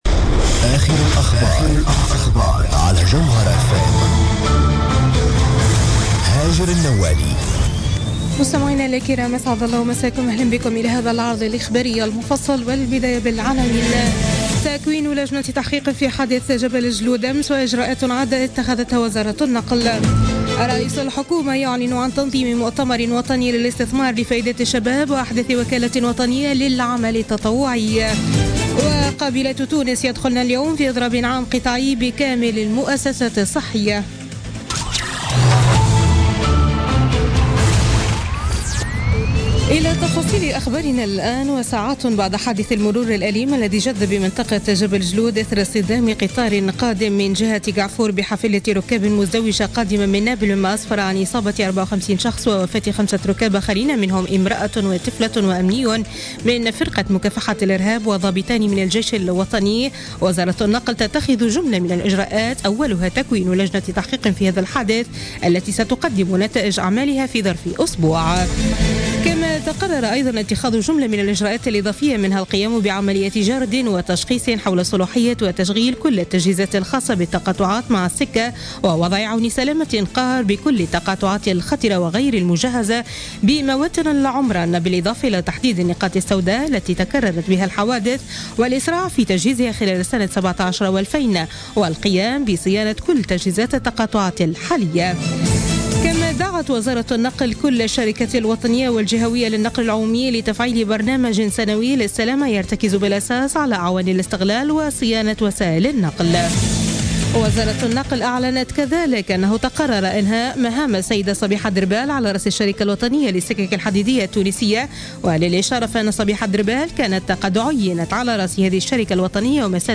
نشرة أخبار منتصف الليل ليوم الخميس 29 ديسمبر 2016